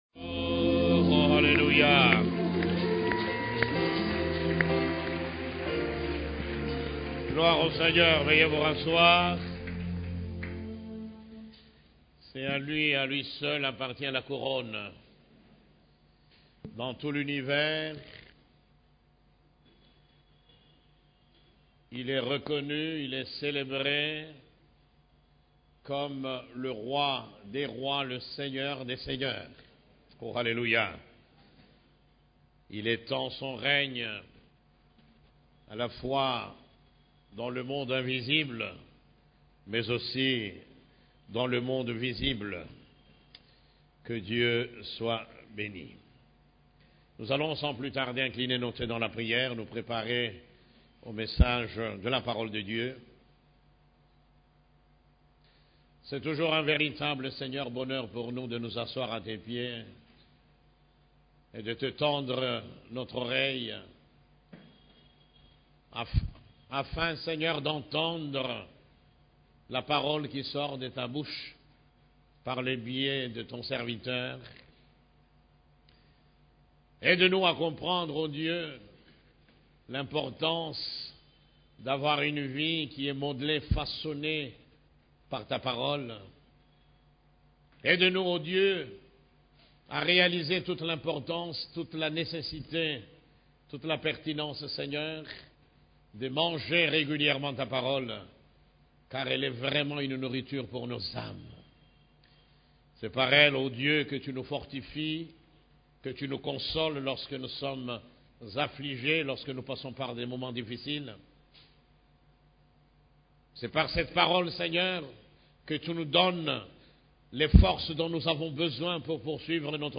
CEF la Borne, Culte du Dimanche, Comment faire face à l'adversité 4